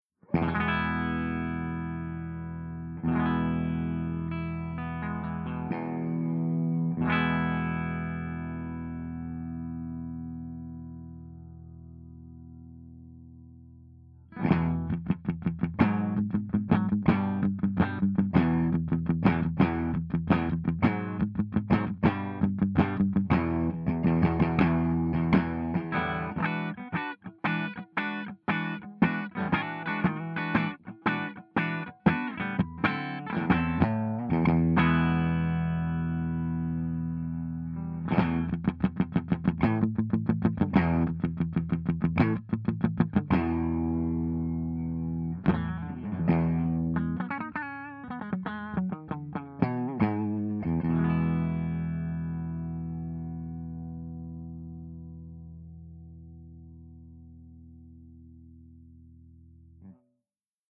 066_FENDERSUPERREVERB_STANDARD_HB.mp3